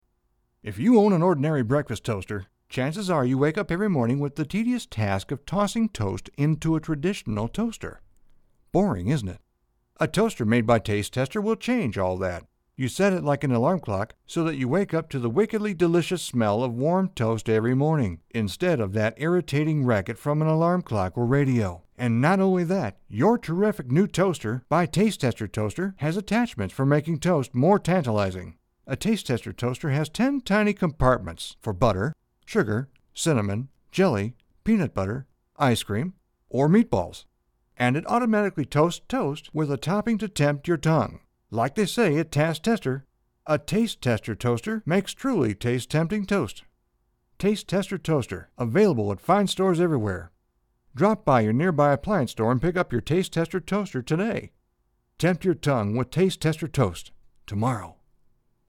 Демо характер Категория: Аудио/видео монтаж